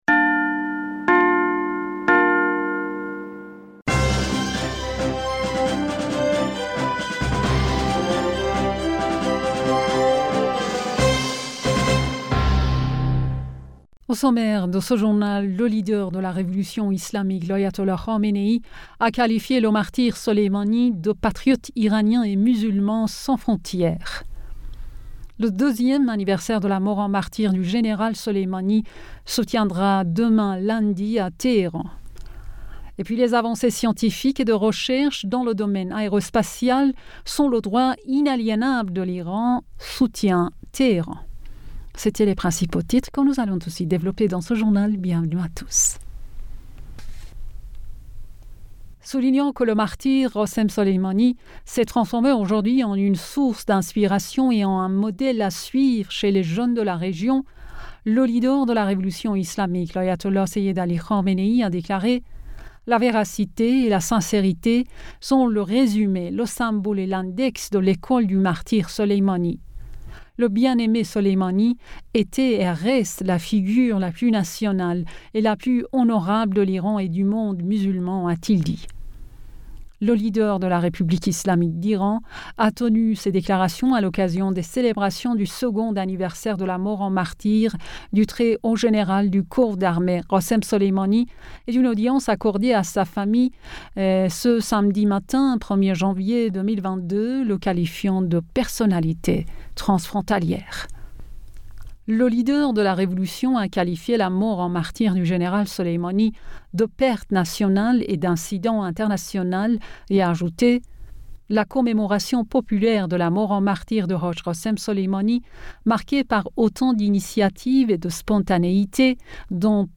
Bulletin d'information Du 02 Janvier 2022